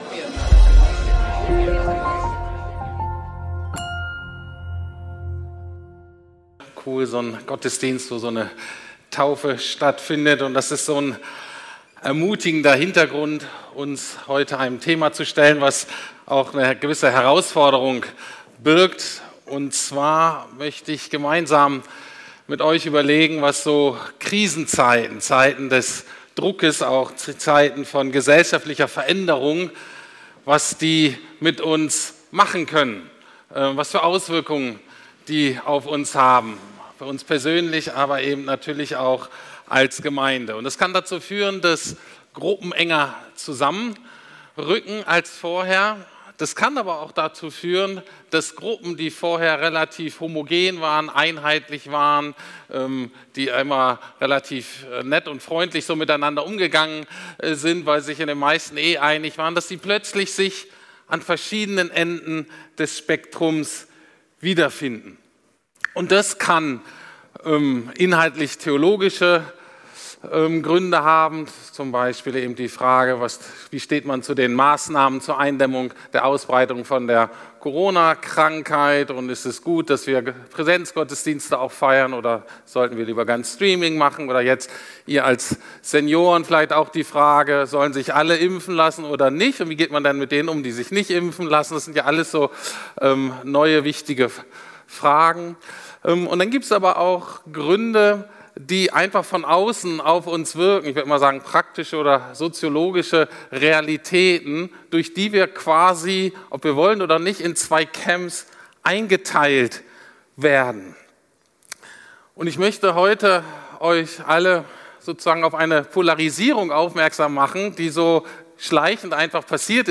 Gemeinde Jesu in und nach Corona ~ Predigten der LUKAS GEMEINDE Podcast